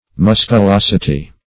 Search Result for " musculosity" : The Collaborative International Dictionary of English v.0.48: Musculosity \Mus`cu*los"i*ty\, n. The quality or state of being musculous; muscularity.